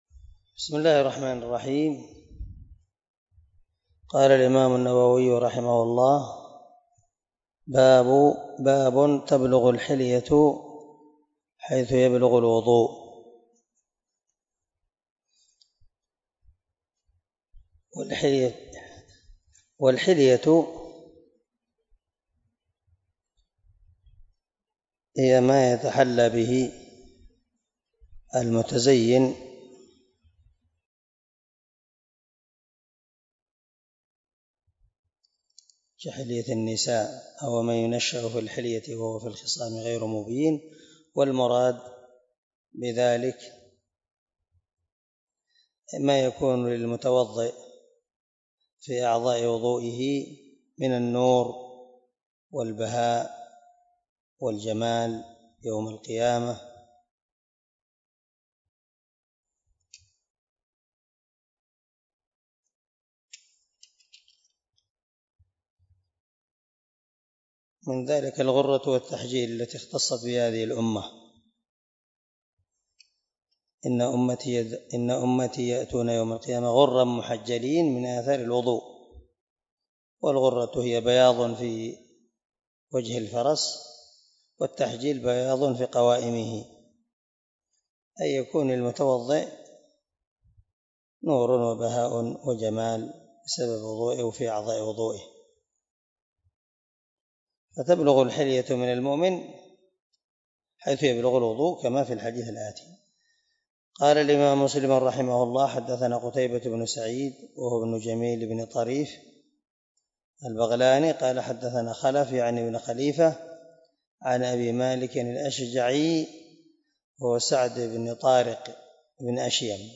187الدرس 15 من شرح كتاب الطهارة حديث رقم ( 250 ) من صحيح مسلم